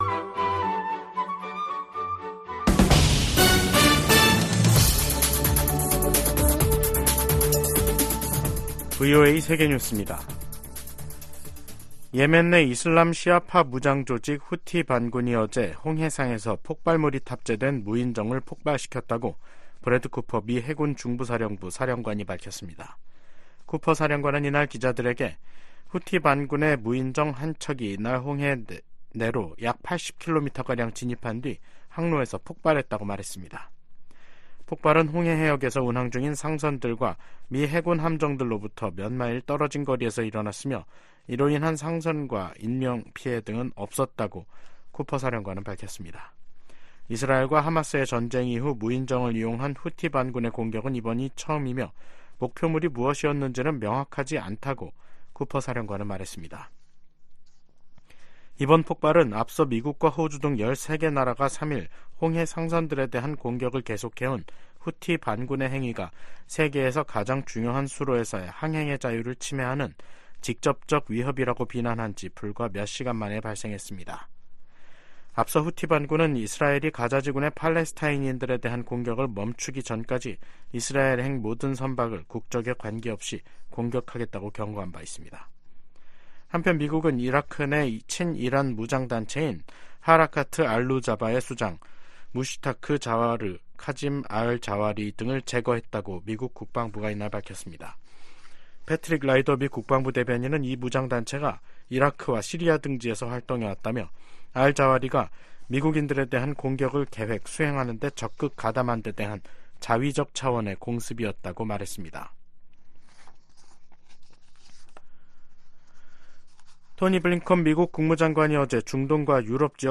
VOA 한국어 간판 뉴스 프로그램 '뉴스 투데이', 2024년 1월 5일 2부 방송입니다. 북한군이 5일 오전 9시부터 서북도서지역에서 해안포 200여발을 발사했습니다. 최근 예멘 후티 반군이 이스라엘을 향해 발사한 순항 미사일 파편에서 한글 표기가 발견됐습니다. 최근 러시아가 북한으로부터 탄도미사일을 제공받아 우크라이나 공격에 사용했다고 미국 백악관이 밝혔습니다.